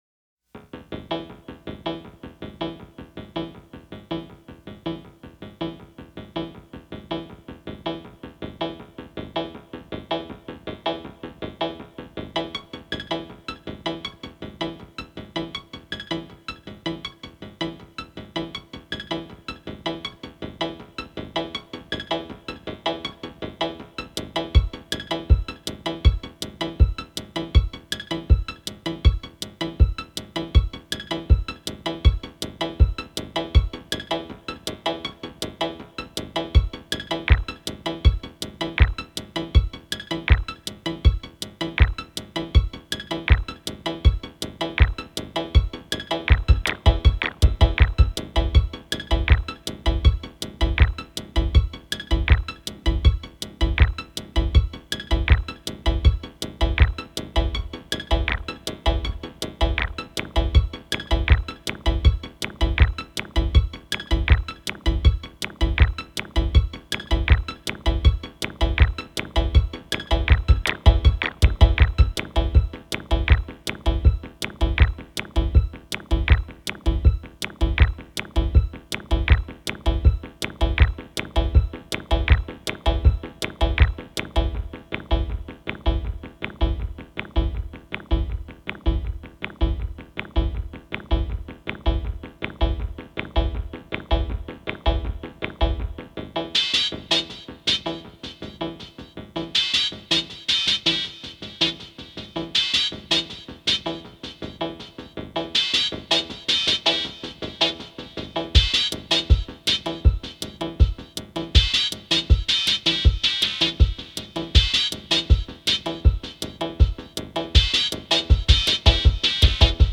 8 Alloy Machines